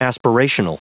Prononciation du mot aspirational en anglais (fichier audio)
Prononciation du mot : aspirational